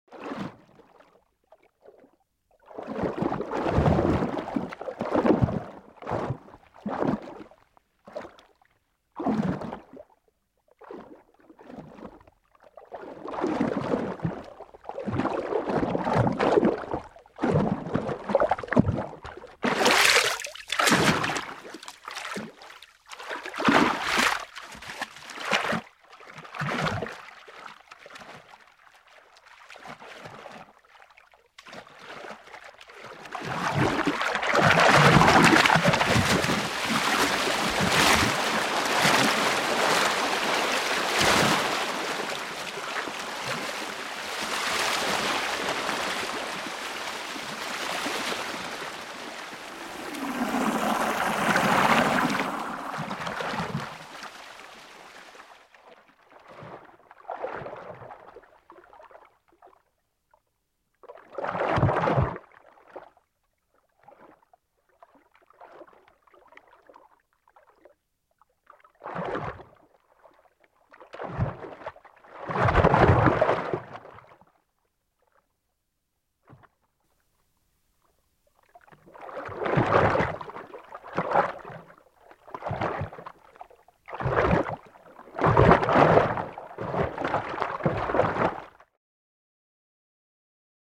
دانلود آهنگ حیوان آبی 7 از افکت صوتی انسان و موجودات زنده
جلوه های صوتی
دانلود صدای حیوان آبی 7 از ساعد نیوز با لینک مستقیم و کیفیت بالا